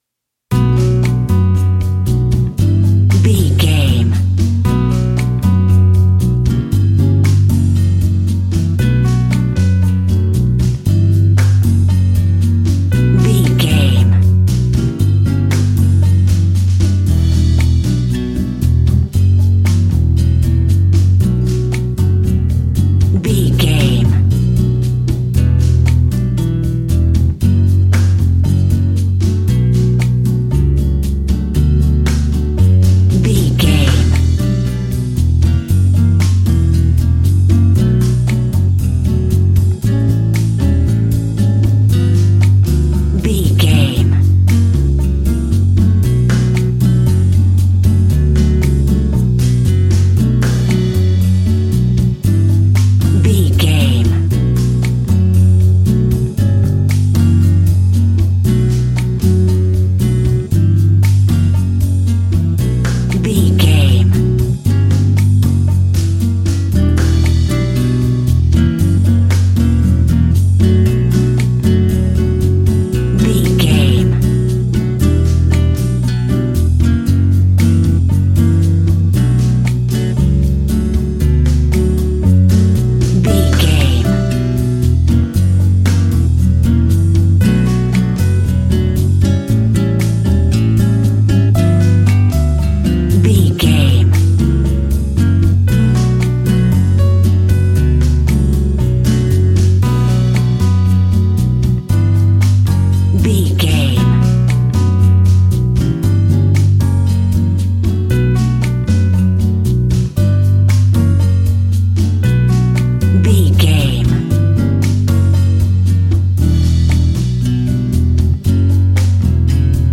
An exotic and colorful piece of Espanic and Latin music.
Aeolian/Minor
flamenco
romantic
maracas
percussion spanish guitar